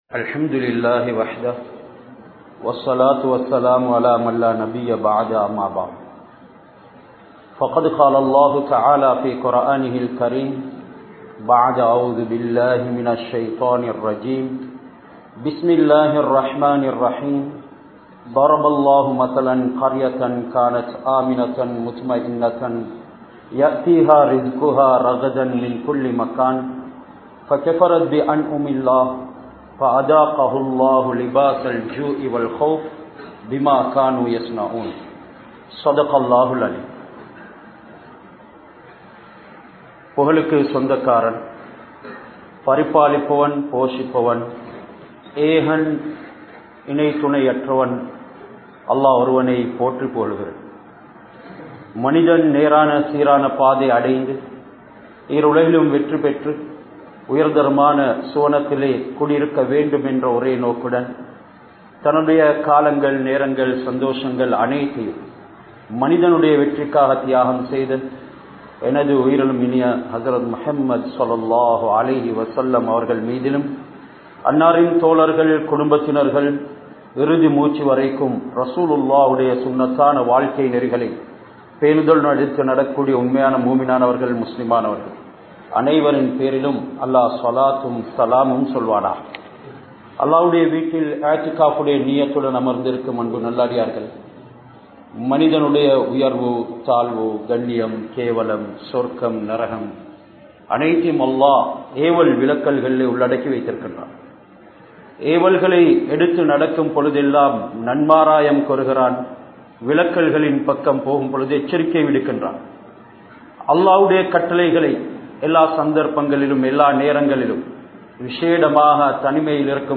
Allahvin Arutkodaihalai Niraahariththaal (அல்லாஹ்வின் அருட்கொடைகளை நிராகரித்தால்) | Audio Bayans | All Ceylon Muslim Youth Community | Addalaichenai